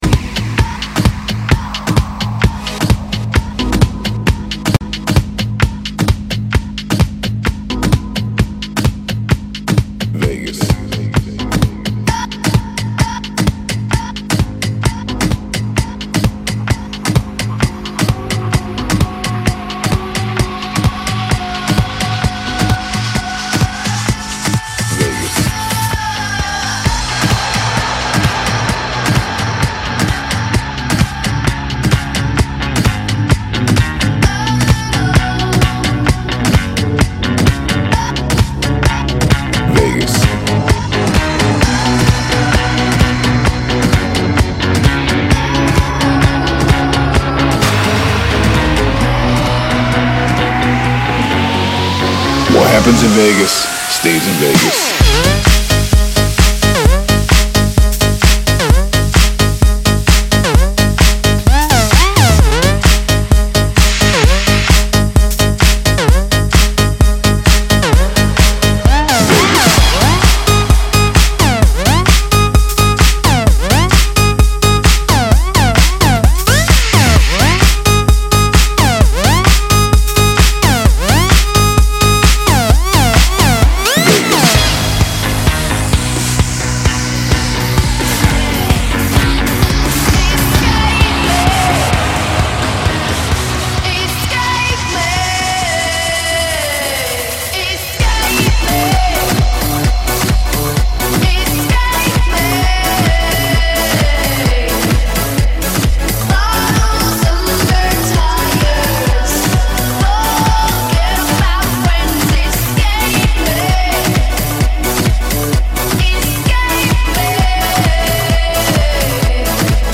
Клубная музыка